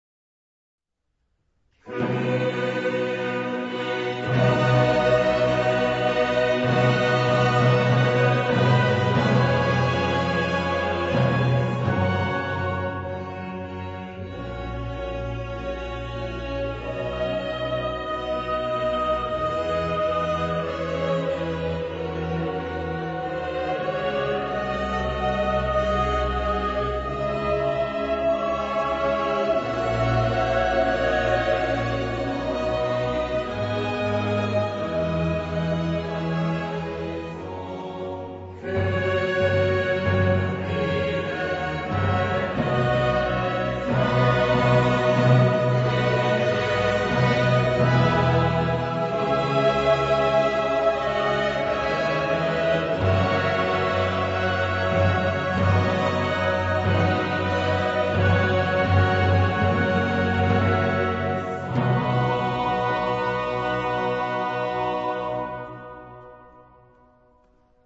Genre-Style-Form: Baroque ; Sacred ; Mass
Mood of the piece: festive
Type of Choir: SATB + SATB  (8 double choir OR mixed voices )
Soloist(s): Sopranos (2) / Altos (2) / Ténors (2) / Basses (2)  (8 soloist(s))
Instrumentation: Chamber orchestra  (16 instrumental part(s))
Instruments: Trumpets (4) ; Timpani (4) ; Violin (4) ; Viola (2) ; Cello (1) ; Organ (1)
Tonality: C major